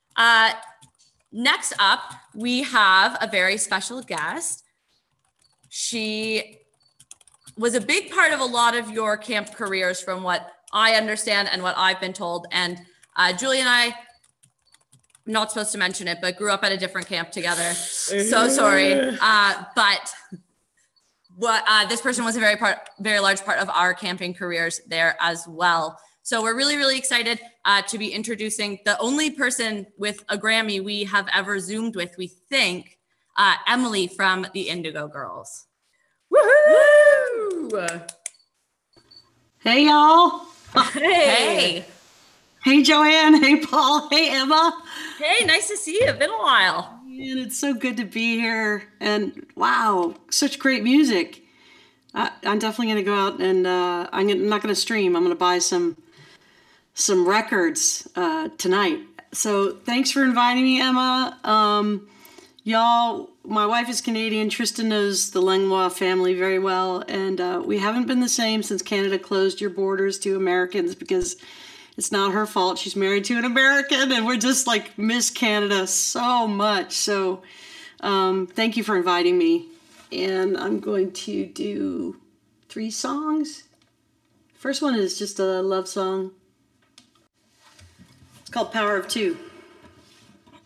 (captured from webcast)
02. interview (emily saliers) (1:30)